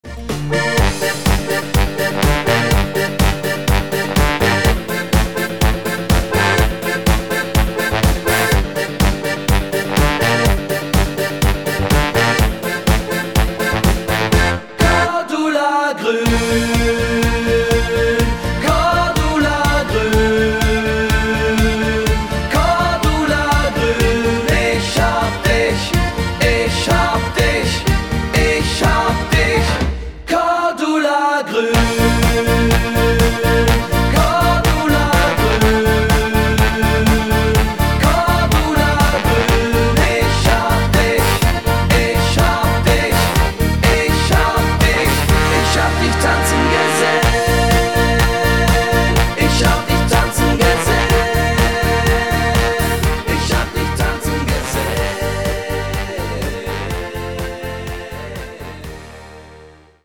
Rhythmus  Party Polka
Art  Alpenfetzer, Blasmusik, Deutsch, Fasching und Stimmung